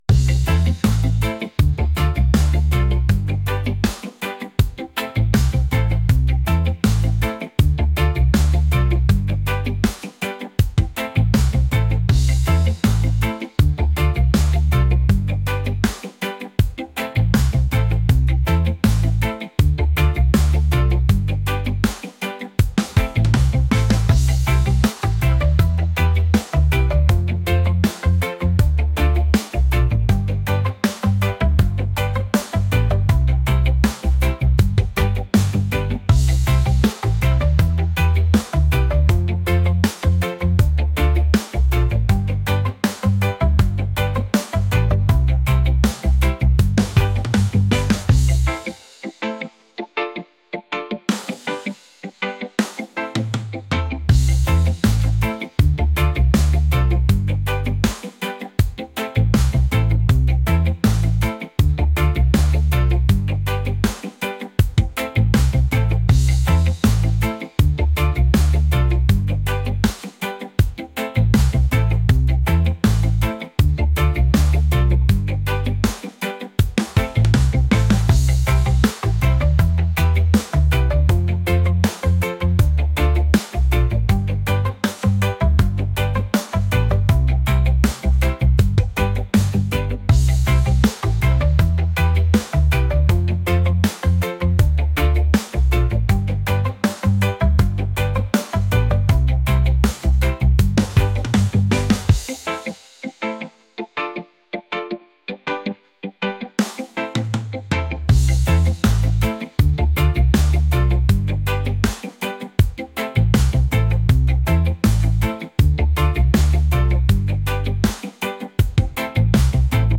reggae | funk | soul & rnb